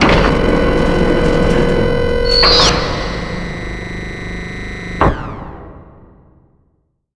1 channel
AmbVirusSwitch.wav